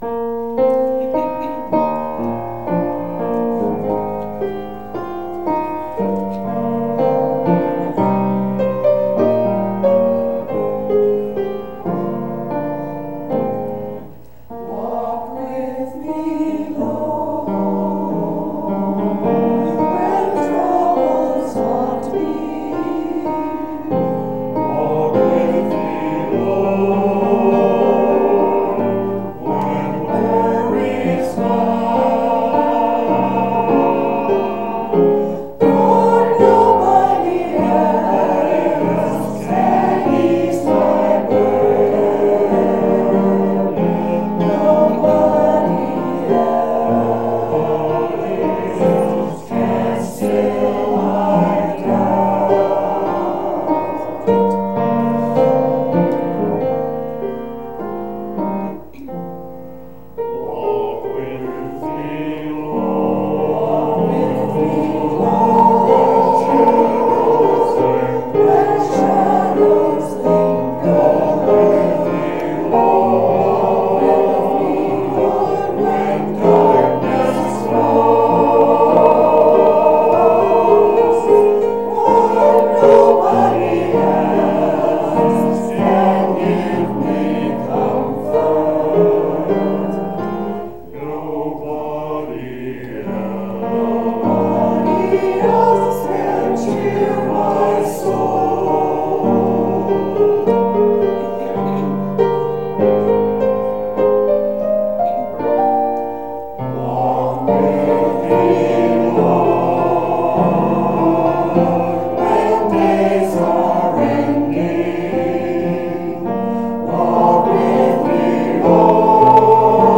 Walk With the Lord – Choir 3.11.18 | Good Shepherd Lutheran Church